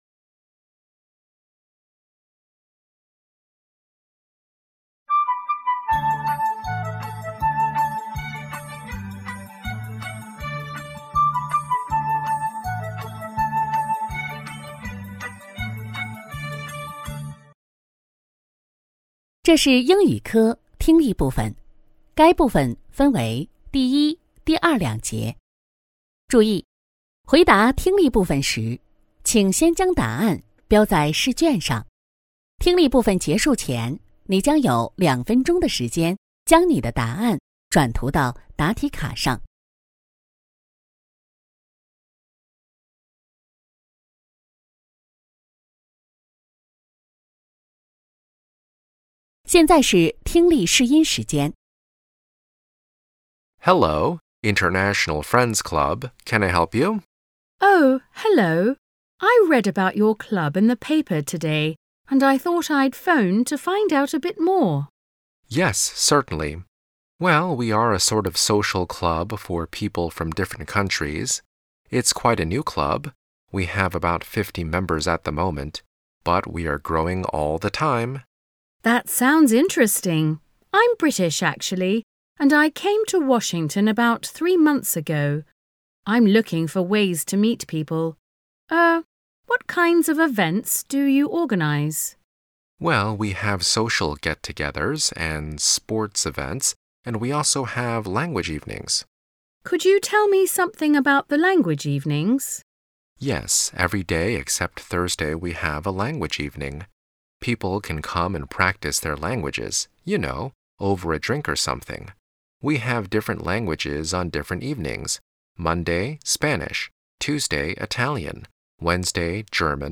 2024届资阳二诊英语听力.mp3